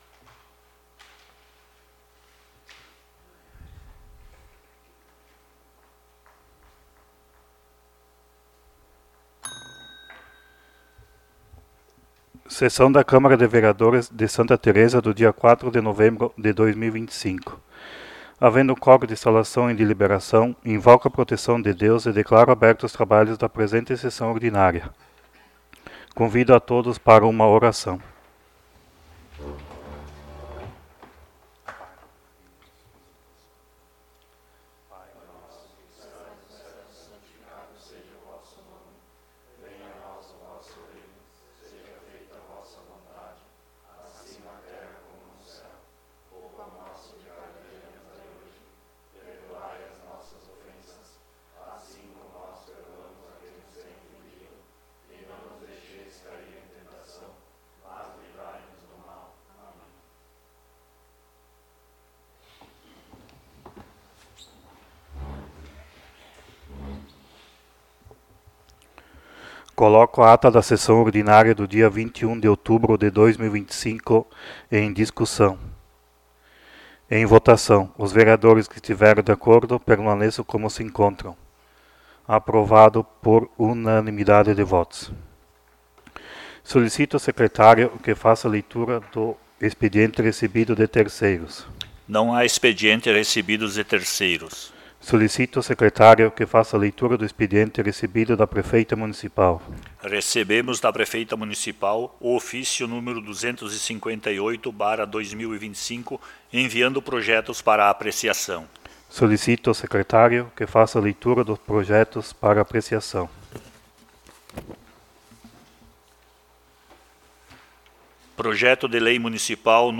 19° Sessão Ordinária de 2025